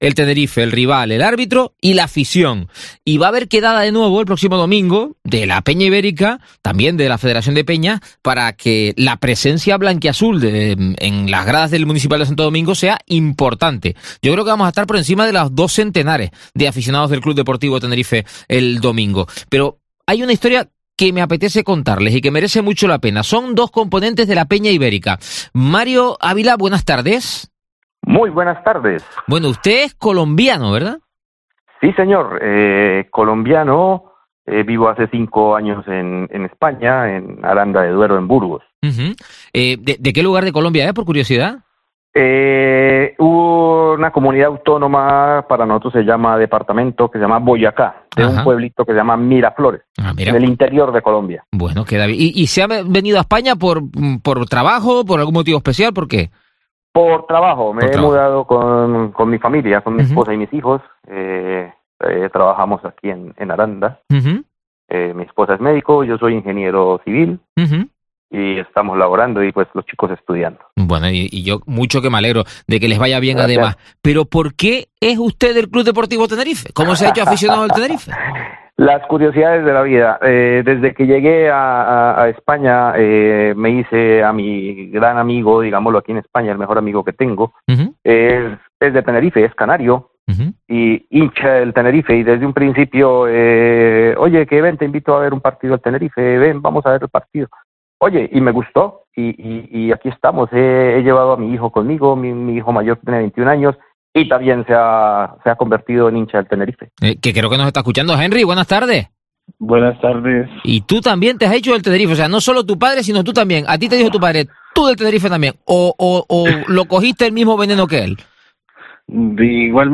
Dos aficionados colombianos seguidores del CD Tenerife